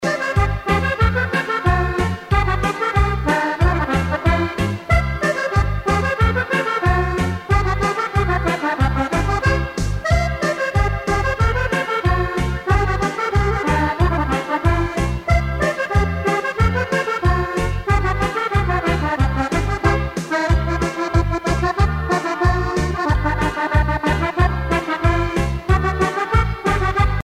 danse : scottich trois pas
Pièce musicale éditée